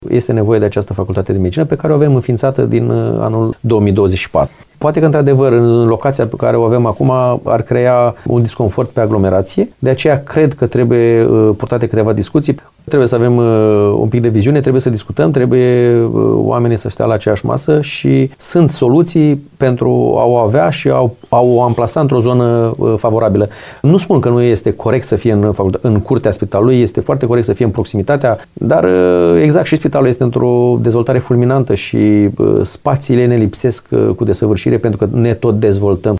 El a declarat postului nostru de radio că principalul neajuns ar fi încărcarea unei zone și așa aglomerate.